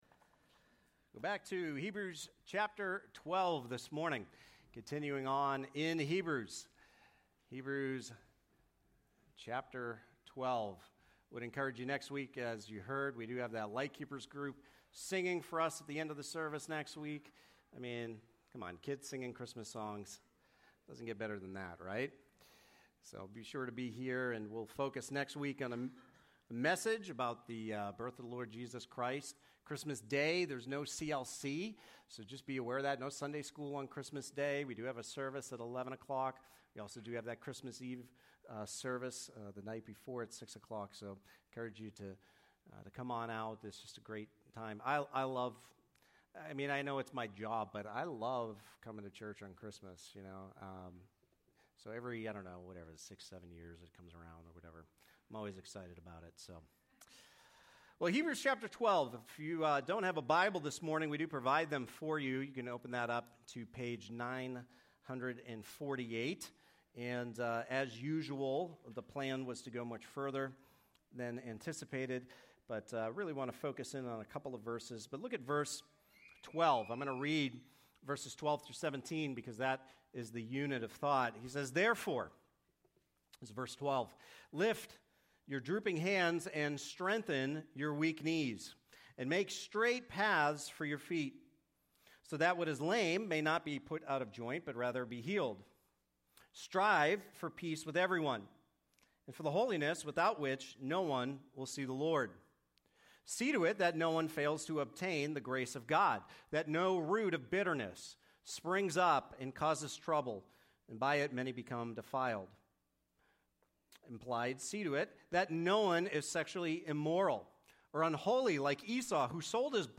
Hebrews: Jesus Is Better - Directives As You Endure - Part 1 Hebrews 12:12-17 Sermon 42